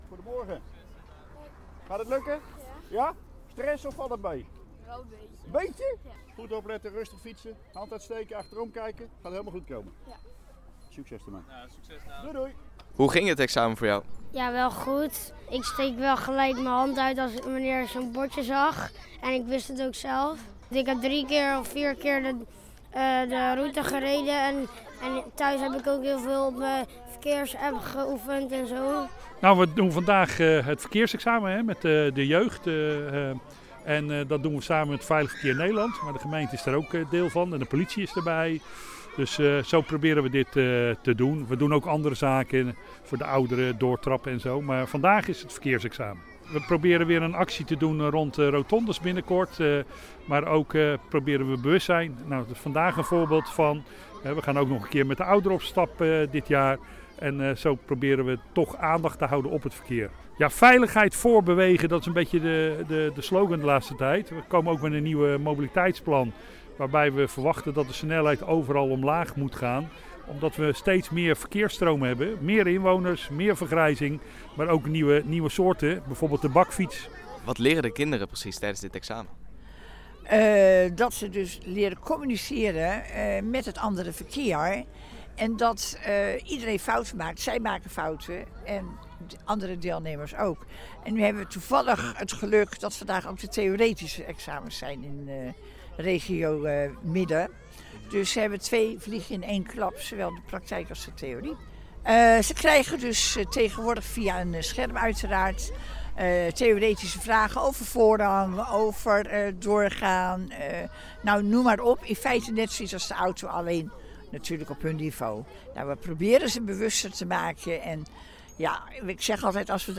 Hieronder de radioreportage met deelnemer